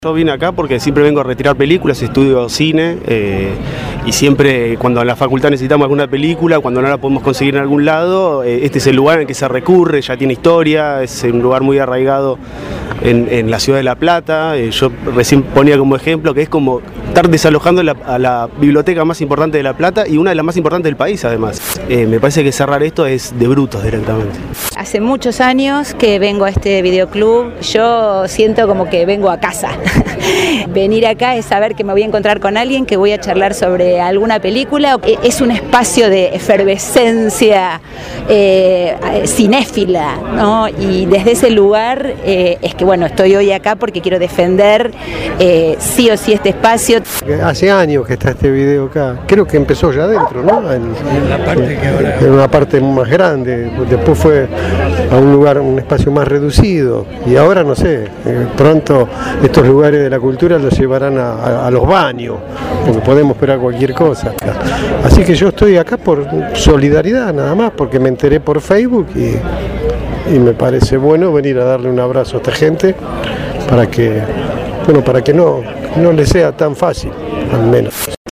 Consultados por Radio Estación Sur, estas fueron algunas de las razones que dieron estudiantes, realizadores locales y vecinos habitués del lugar para defenderlo.